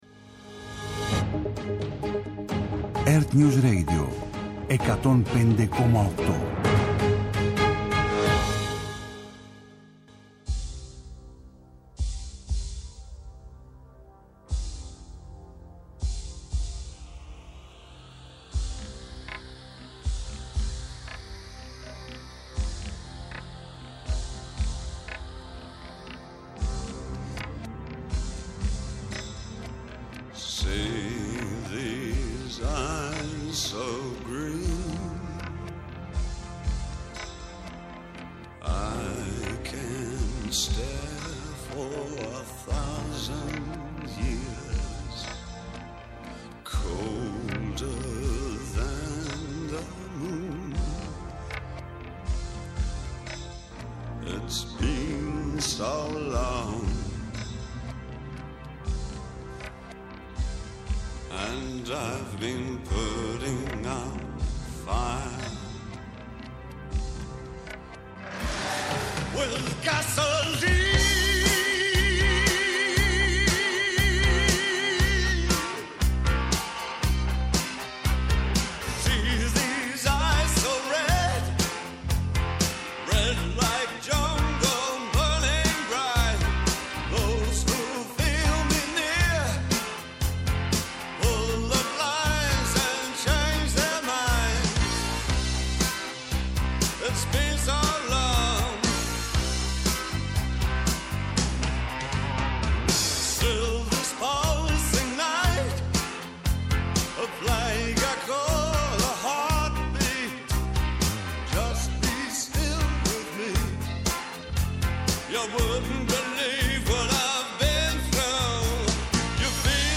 Νυχτερινές ιστορίες με μουσικές και τραγούδια που έγραψαν ιστορία.